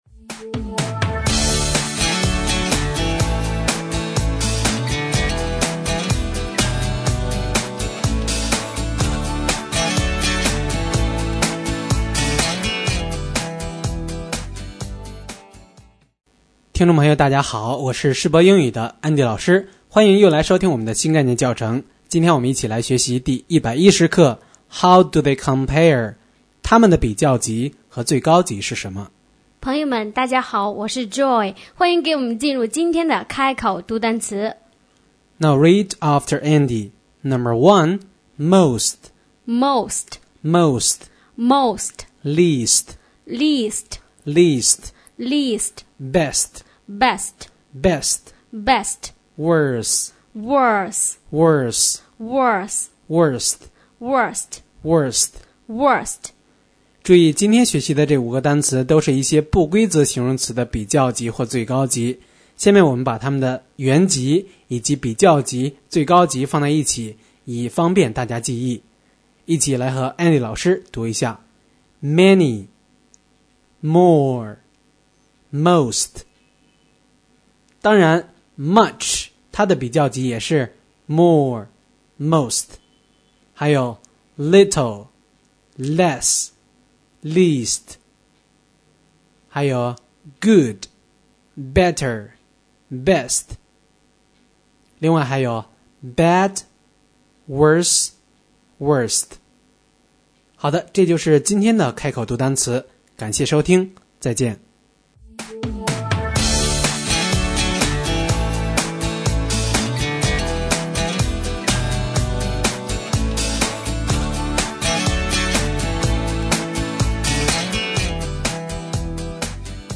新概念英语第一册第110课【开口读单词】